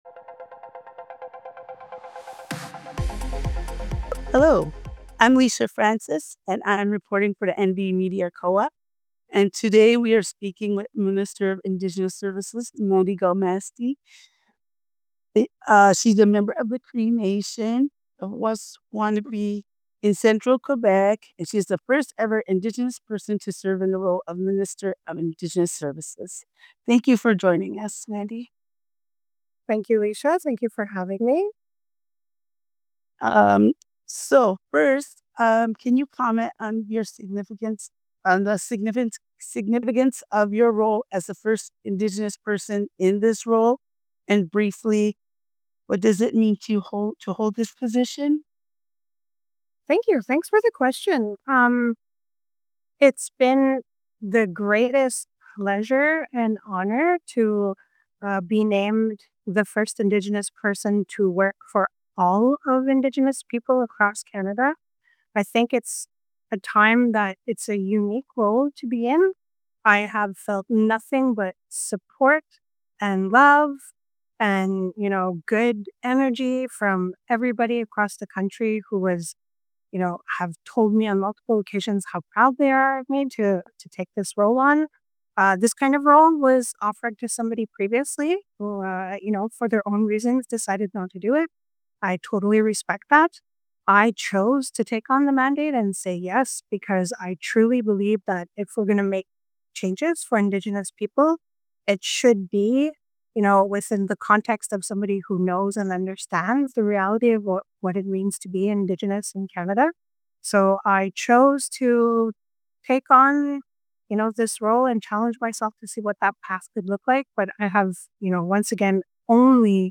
interview-minister-of-indigenous-services-says-very-discriminatory-second-generation-cut-off-needs-multifaceted-solution